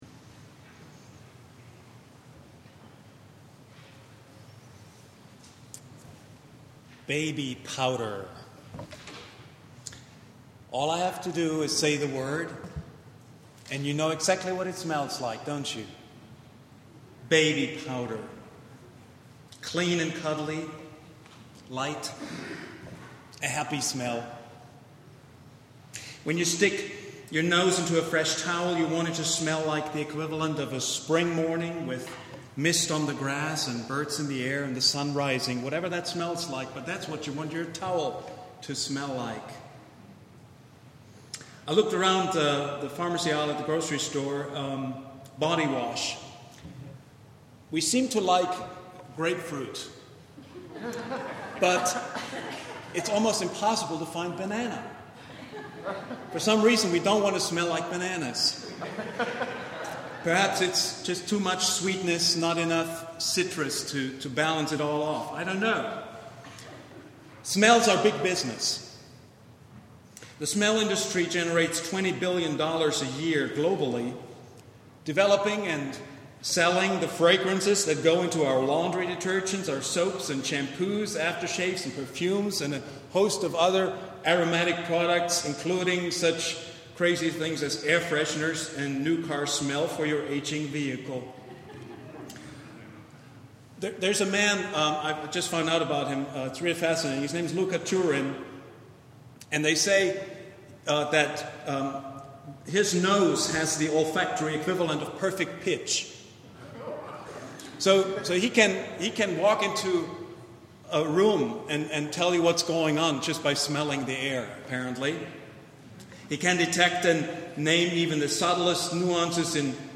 The Sweet Aroma of Love — Vine Street Christian Church